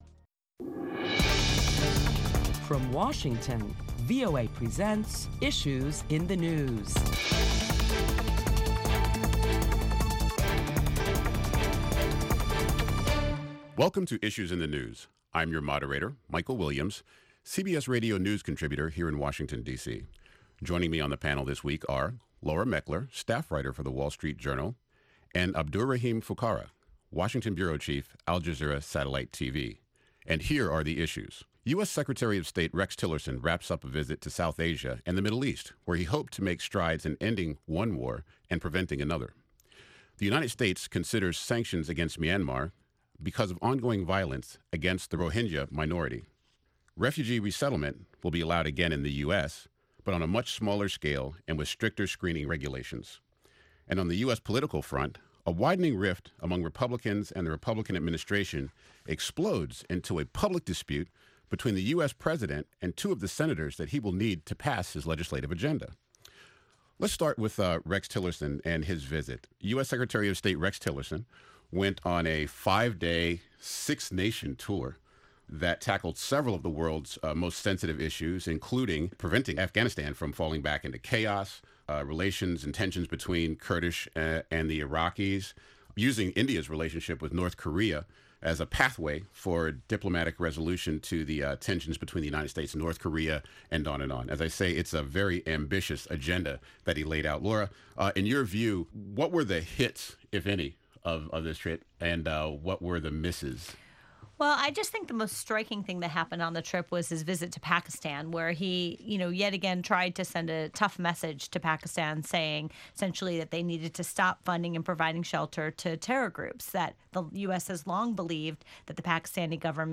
Prominent Washington correspondents discuss the week’s top headlines – including U.S. Secretary of State Rex Tillerson’s recent trip to South Asia and a resumption of refugee admissions to the U.S., though with a stricter vetting process.